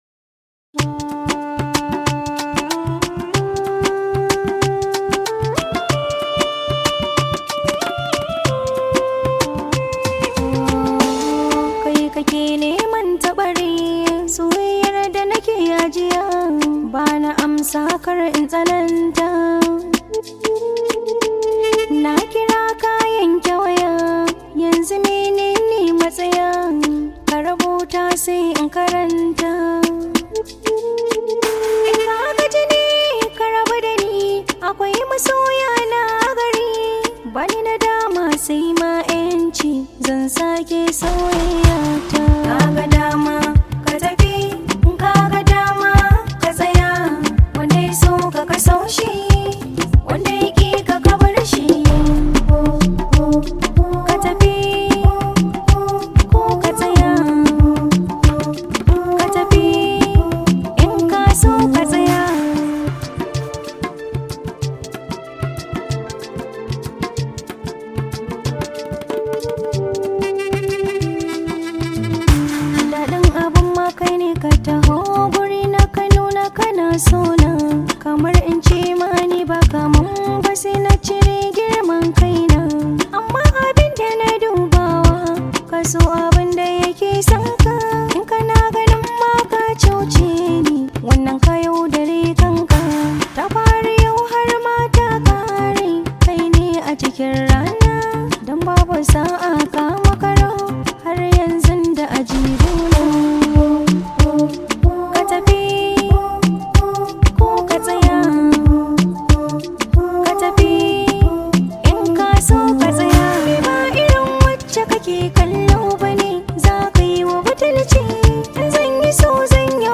Hausa love song